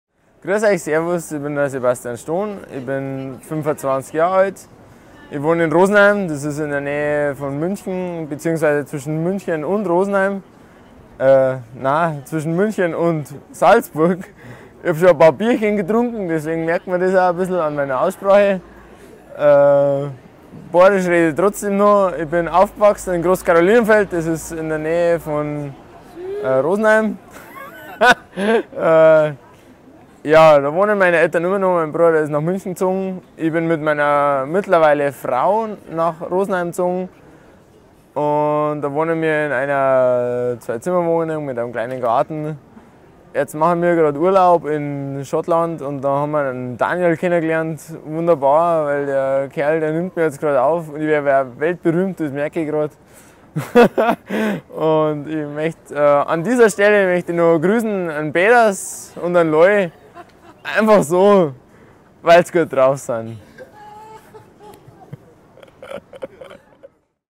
13. 바이에른어 방언 예시
바이에른어 사용